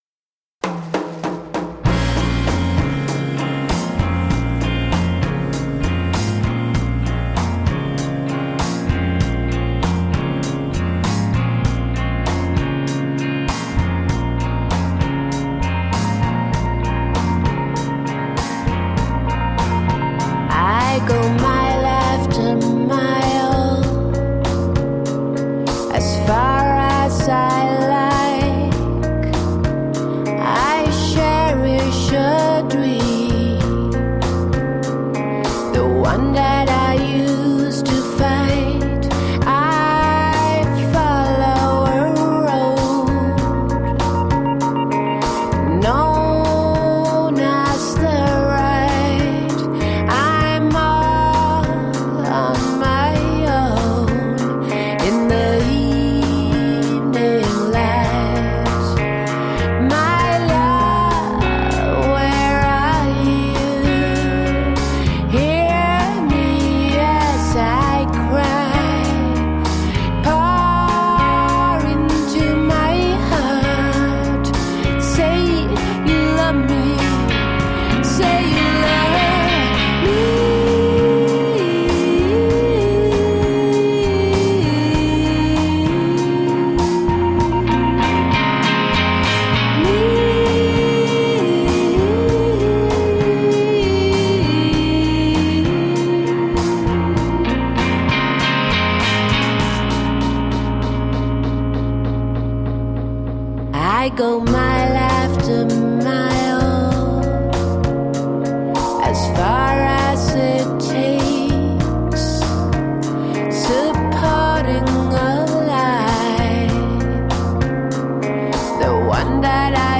Groovy downtempo rock.
Alt Rock, Other, Downtempo